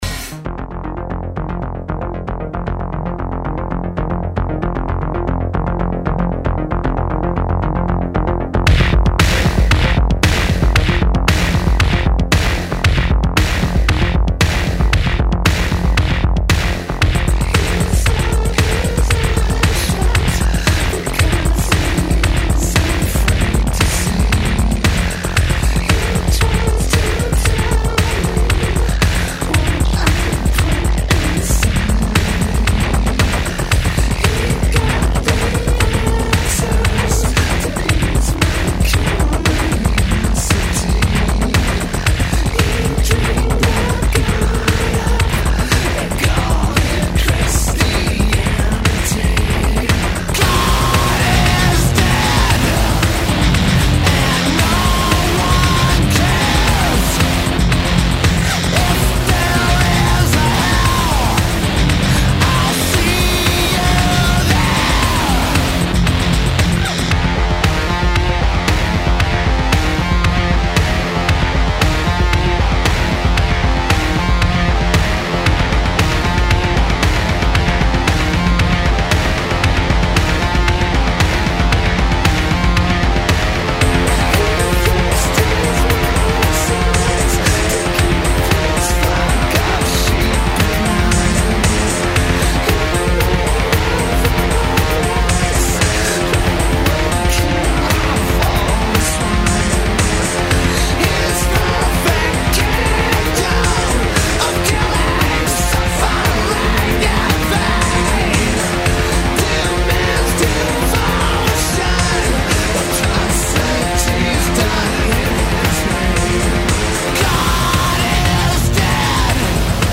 La musica come unico raccordo capace di intrecciare più racconti.